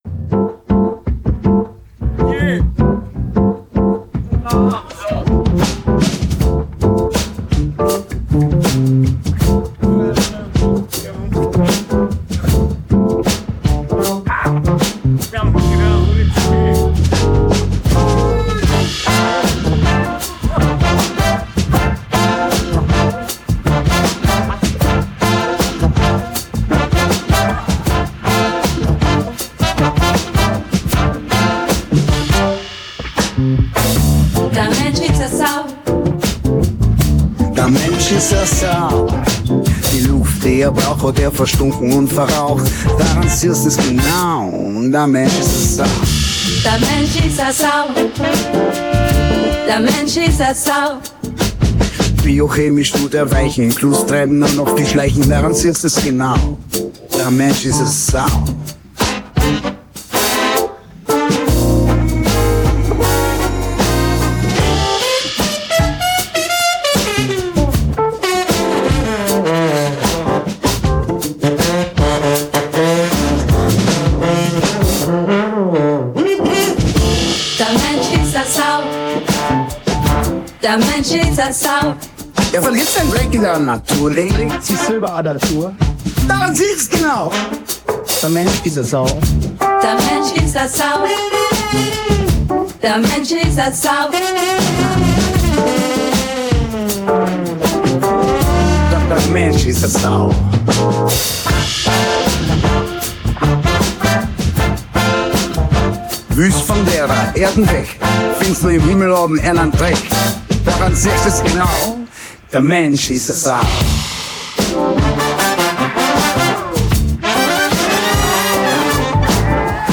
War so'ne RnB Nummer mit bayrischem/österreichischem (weiß nicht mehr) Dialekt. Das groovte unglaublich locker daher und war eben alles nicht komplett auf dem Grid.